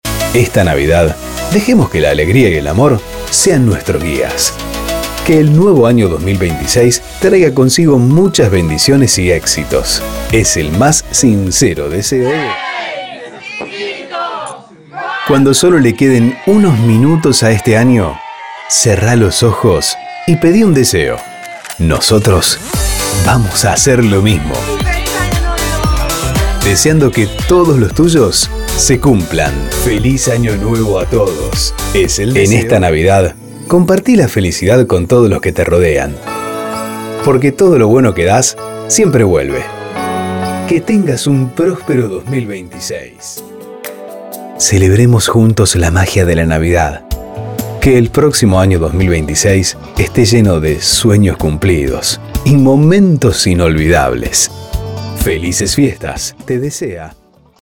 Salutaciones para venderle a tus anunciantes o personalizarlas con los datos de tu radio.